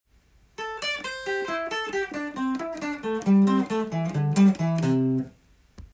エレキギターのピッキング練習に最適【Amペンタトニックスケール４度シーケンス】tab譜つくってみました
ギタリストが頻繁に使うパターンがマイナーペンタトニックスケール４度シーケンスです。
①は、開放弦が入ったパターンです。